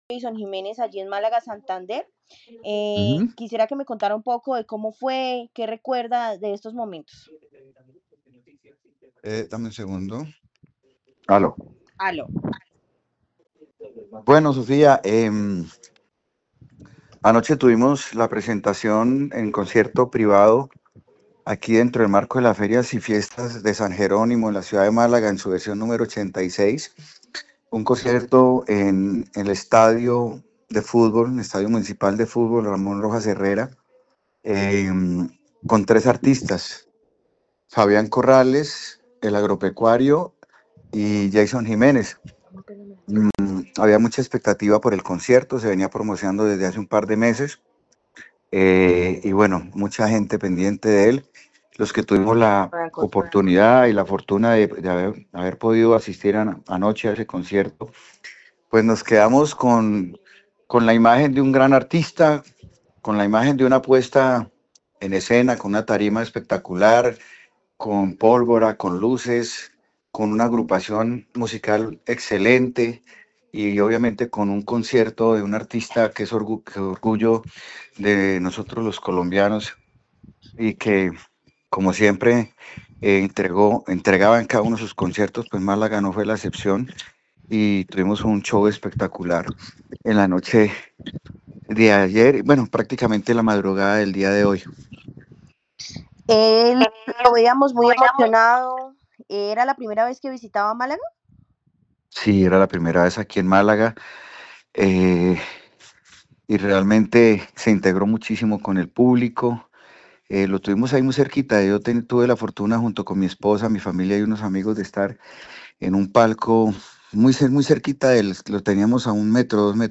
Rubén Darío Moreno, alcalde de Málaga